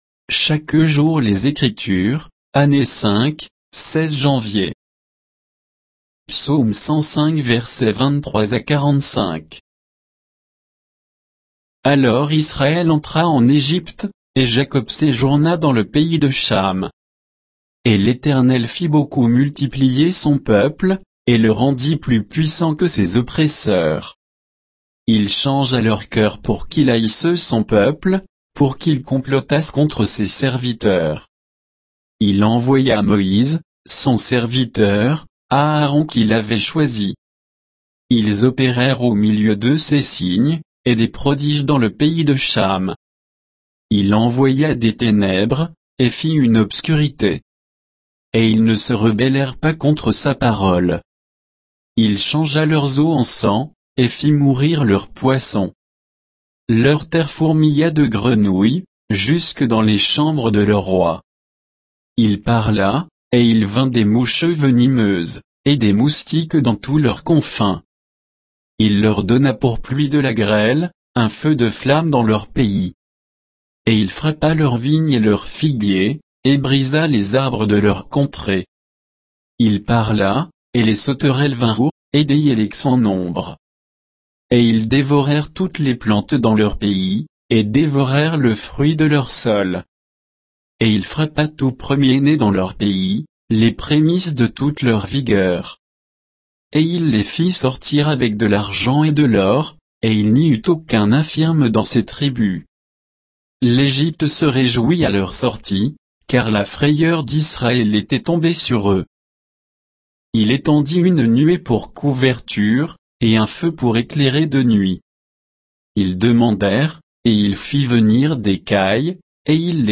Méditation quoditienne de Chaque jour les Écritures sur Psaume 105